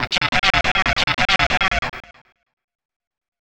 Techno / Voice / VOICEFX234_TEKNO_140_X_SC2(R).wav
1 channel